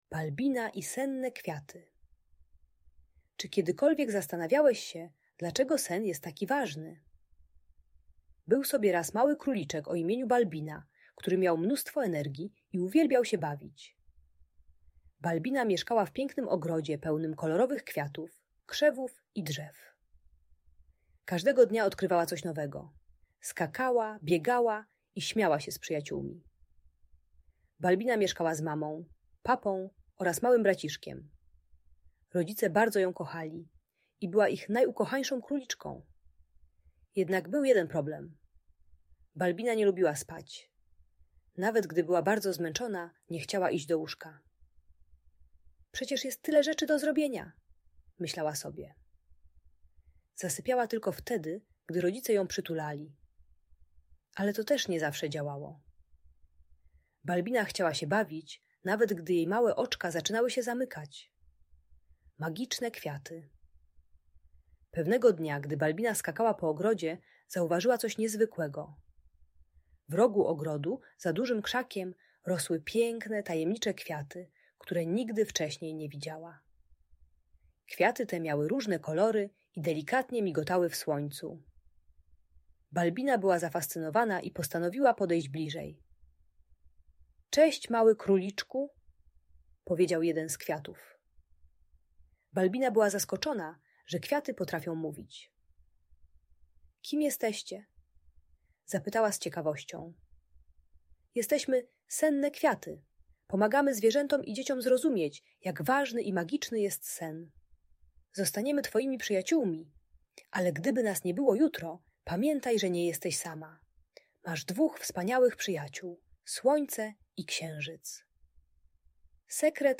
Balbina i Senne Kwiaty - Audiobajka